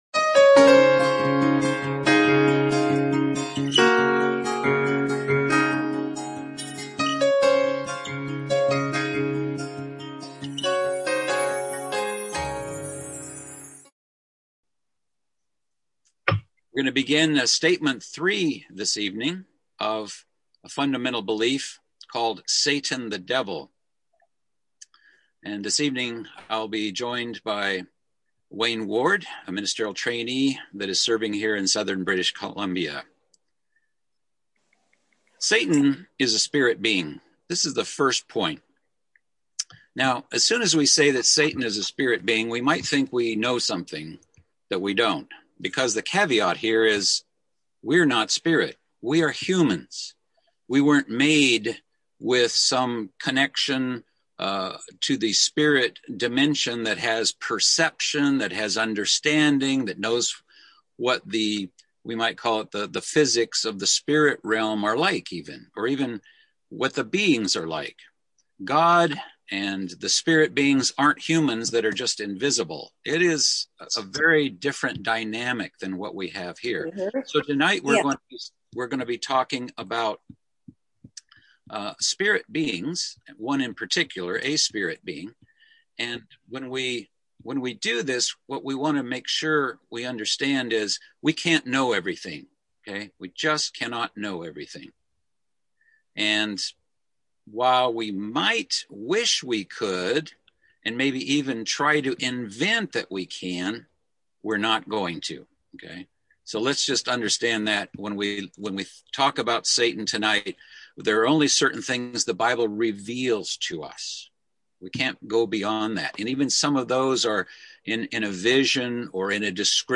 Fundamental Biblical Beliefs - Bible Study